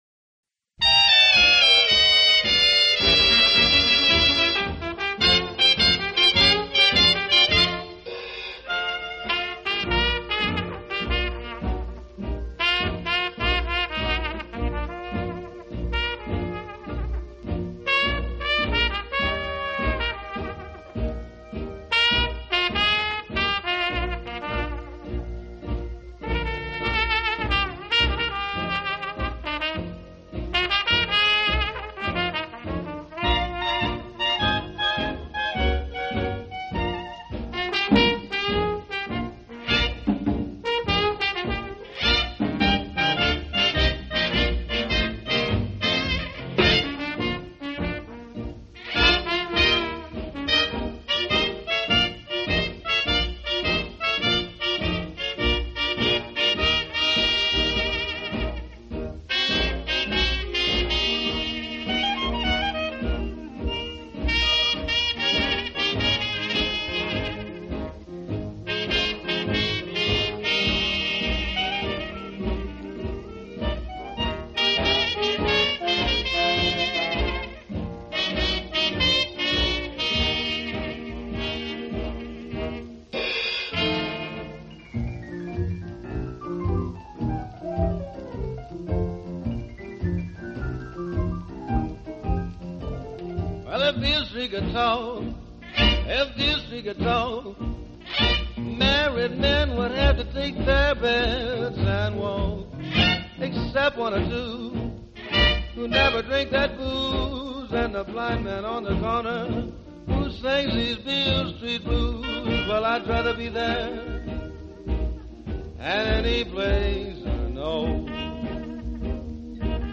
专辑语种： 纯音乐
专辑类型： 爵士